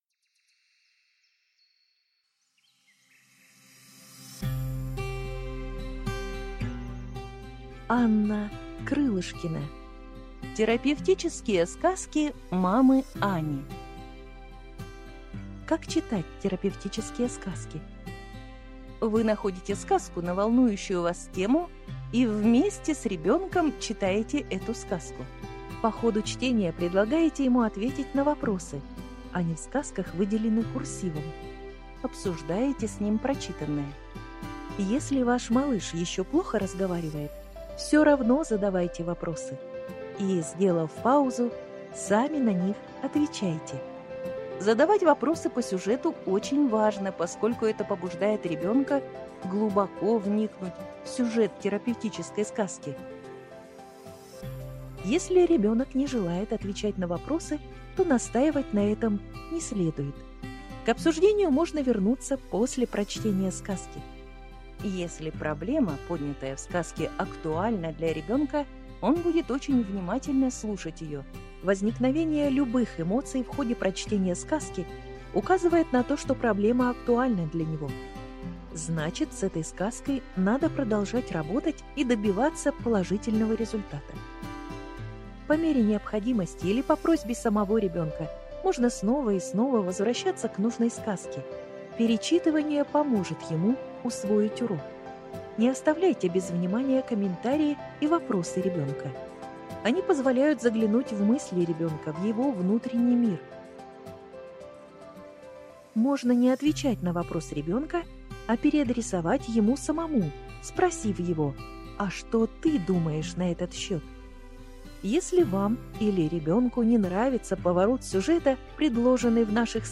Аудиокнига Терапевтические сказки мамы Ани | Библиотека аудиокниг